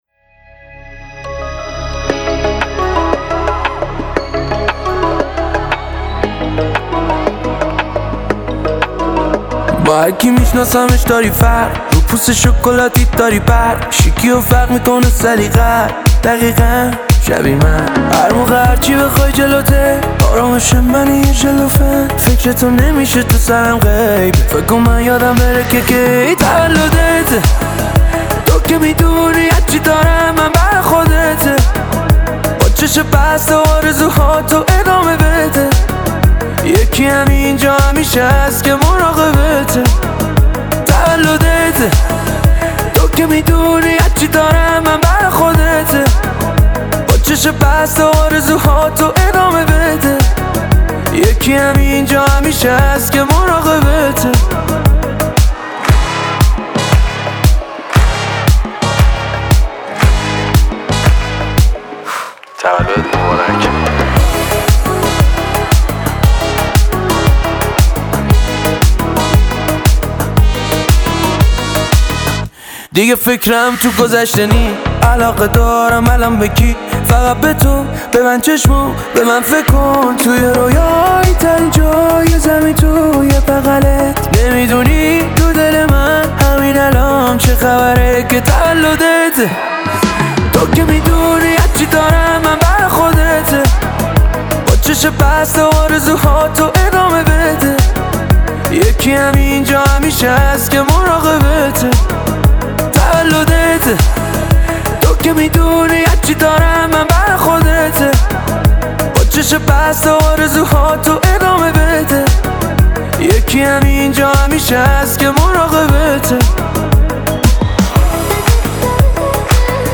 موسیقی پاپ ایرانی